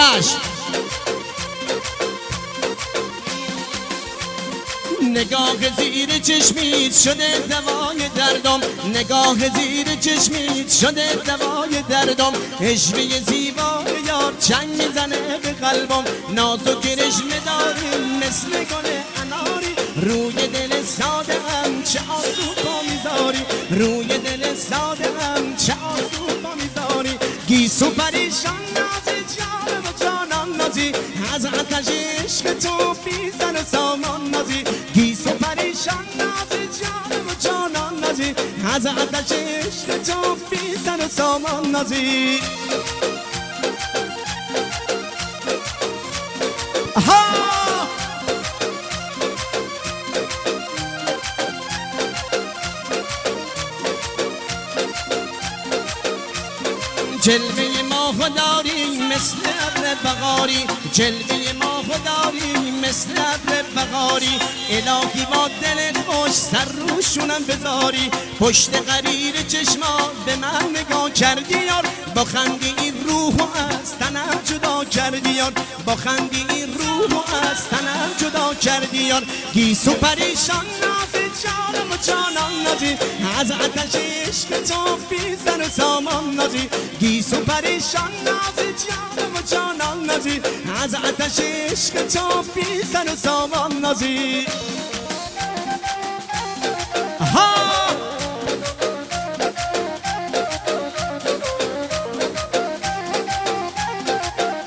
آهنگ محلی